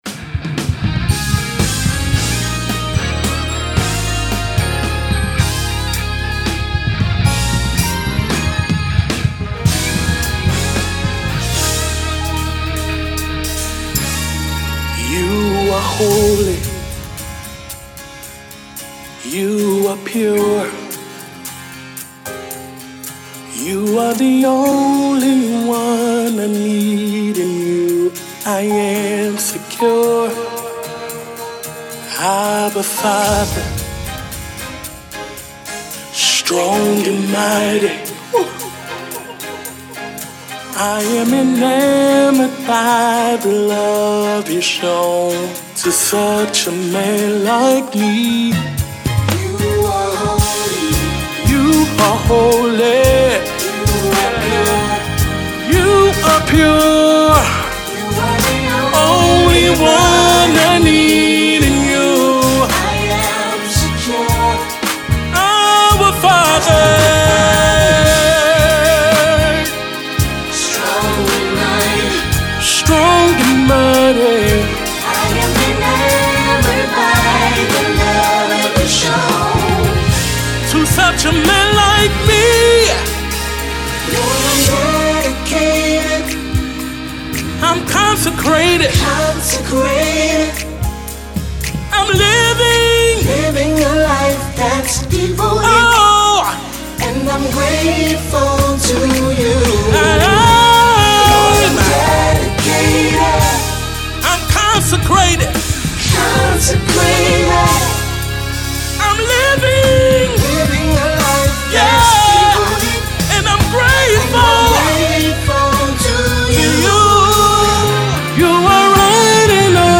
Indie Gospel